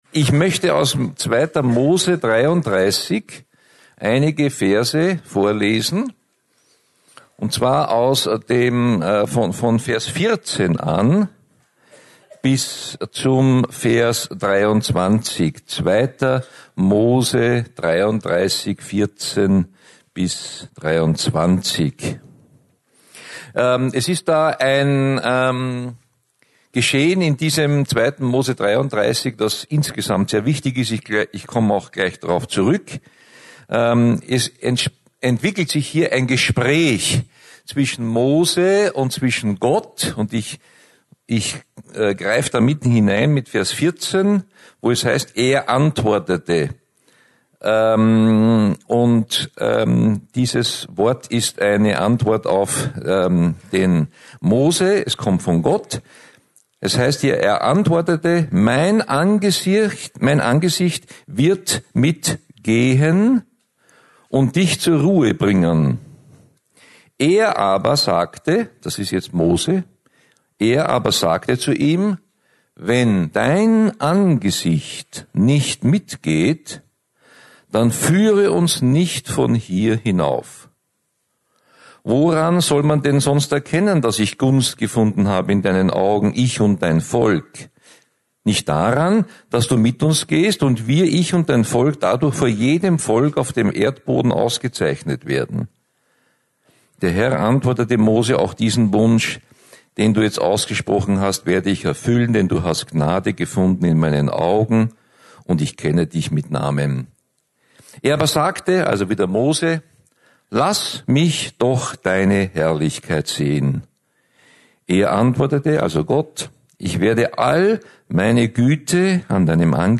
Hier finden Sie das Predigt Archiv für das Jahr 2017.